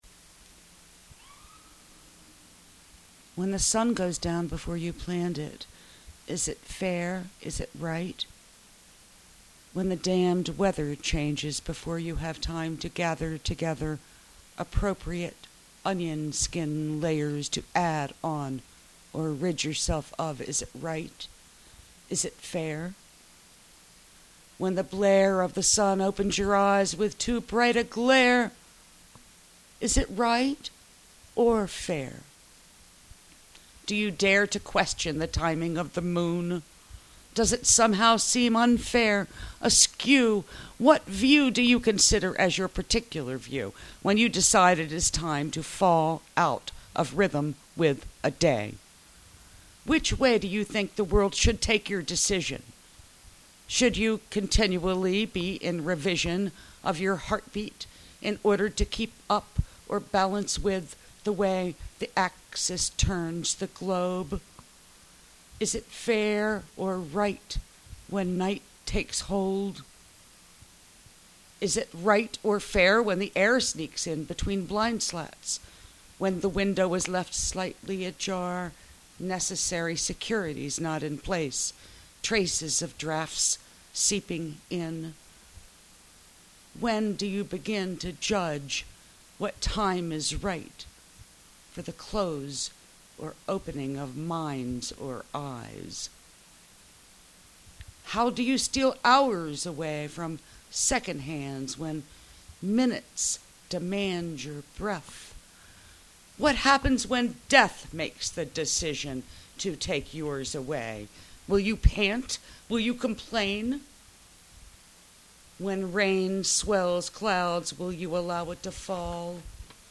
You put a nice edge on it. Something so down home in your voice, sounds Maryland, almost baltimore.
Thank you for listening to my reading. it was something to do....